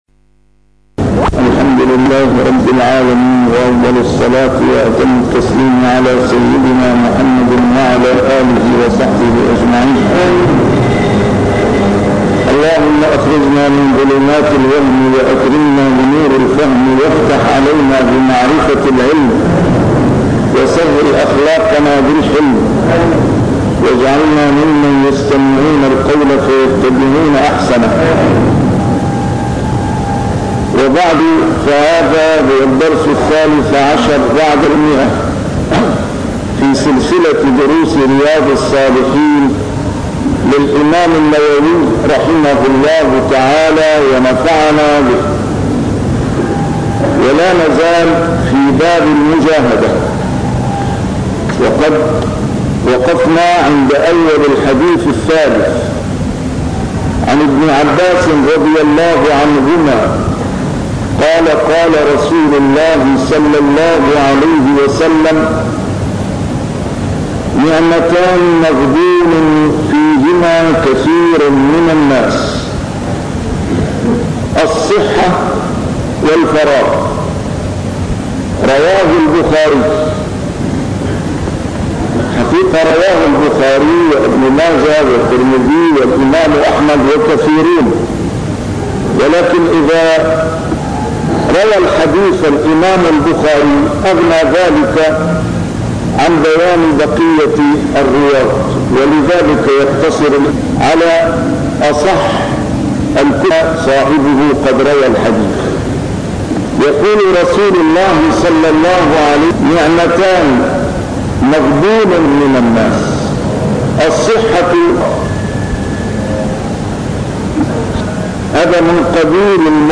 A MARTYR SCHOLAR: IMAM MUHAMMAD SAEED RAMADAN AL-BOUTI - الدروس العلمية - شرح كتاب رياض الصالحين - 113- شرح رياض الصالحين: المجاهدة